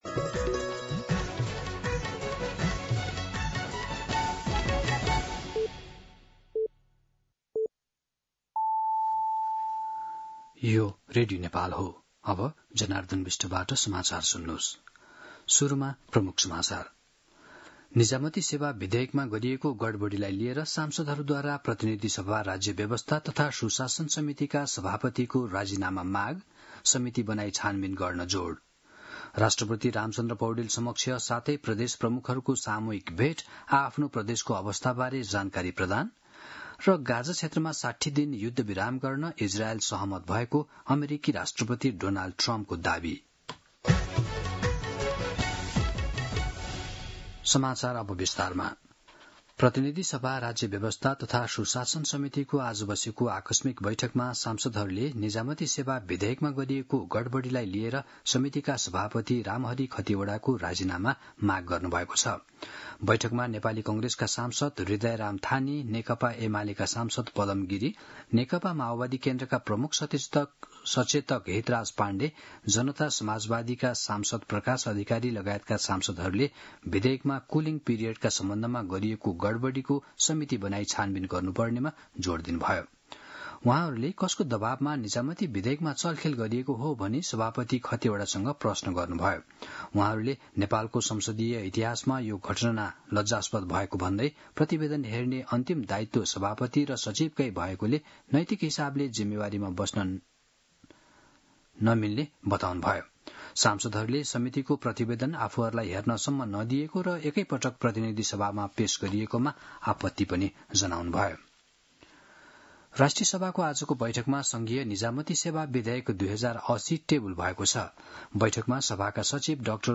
दिउँसो ३ बजेको नेपाली समाचार : १८ असार , २०८२
3-pm-News-18.mp3